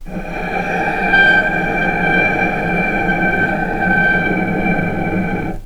vc_sp-G5-pp.AIF